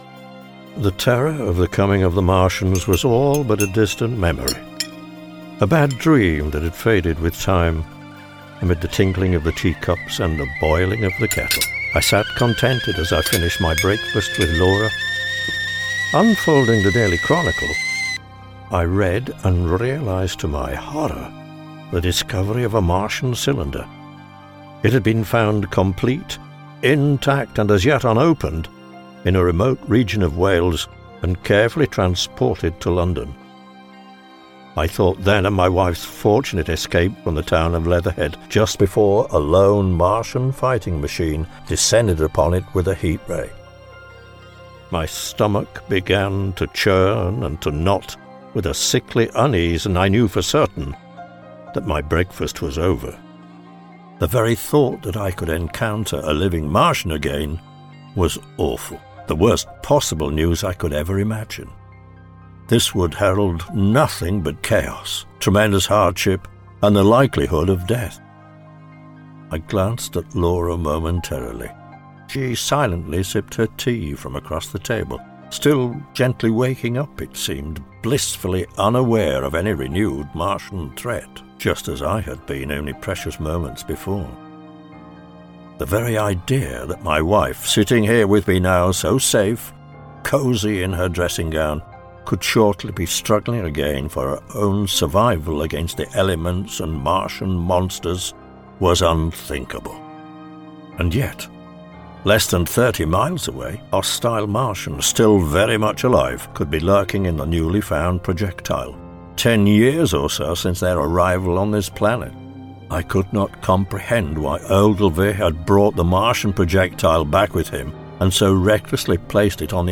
Audiobook: $ 5.95
The first volume in this sequel to The War Of The Worlds was originally imagined purely as an audio with bespoke music and sound effects. For that purpose, the chapters were written in visual scenes' some of which are quite short, as the music was designed to create setting and atmosphere.